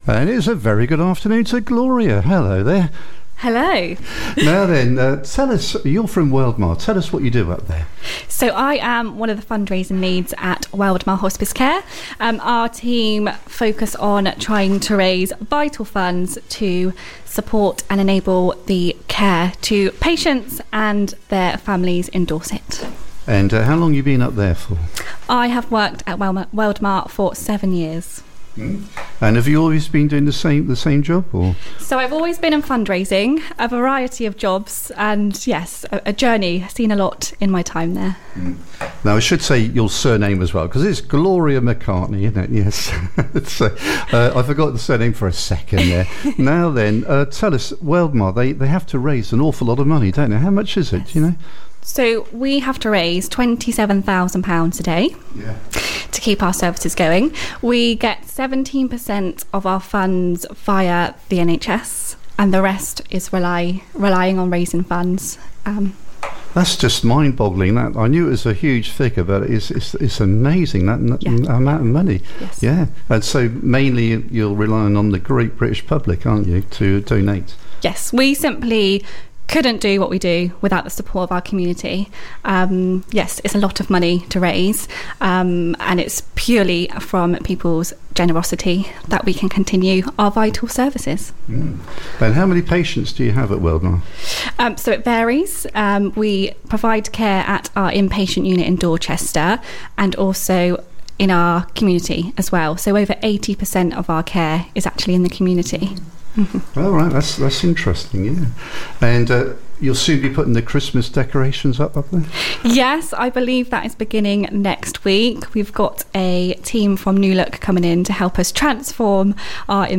The Community Radio Station covering Central-Southern Dorset, run by volunteers and not-for-profit